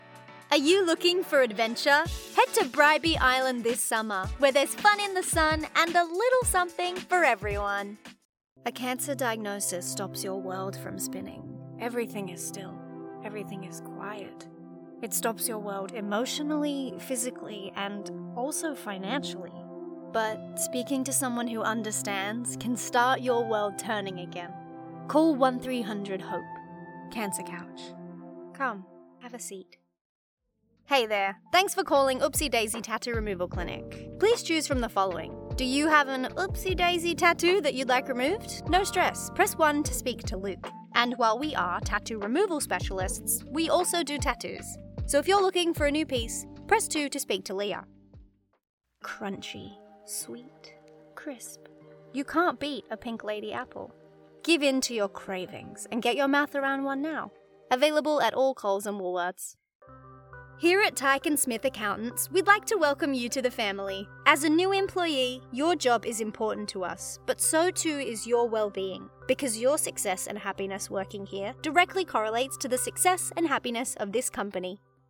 Female
English (Australian)
My vocal quality is bright, authentic and engaging.
I record in a sound treated studio at home using a Rode NT USB+ microphone.
Radio Commercials
Aus Accent Commerical
1208Aus_commerical_edit.mp3